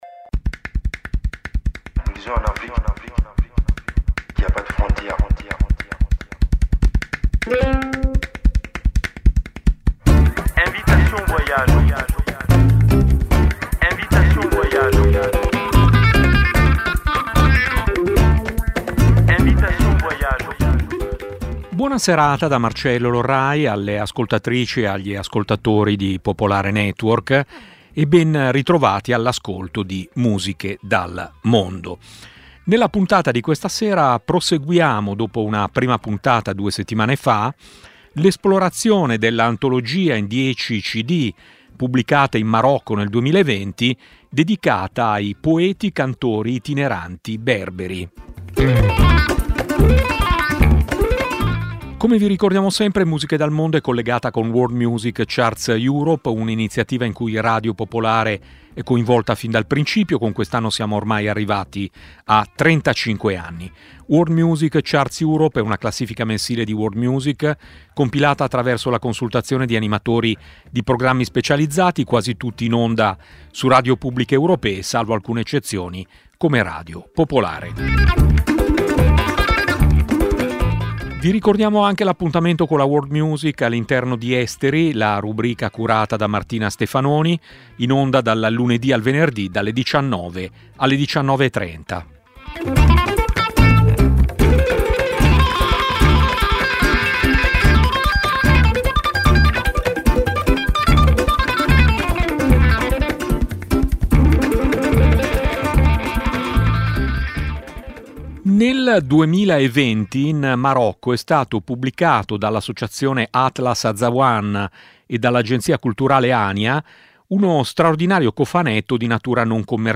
Musiche dal mondo è una trasmissione di Radio Popolare dedicata alla world music, nata ben prima che l'espressione diventasse internazionale.
Un'ampia varietà musicale, dalle fanfare macedoni al canto siberiano, promuovendo la biodiversità musicale.